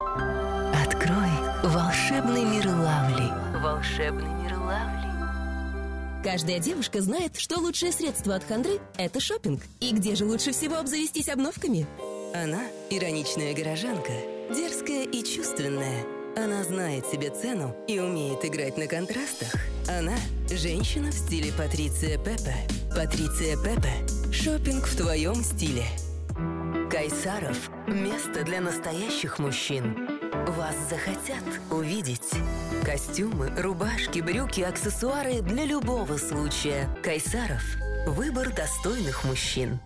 Тракт: микрофон SE Electronics x1,звуковая карта Focusrite Scarlett Solo 2nd Gen
Демо-запись №1 Скачать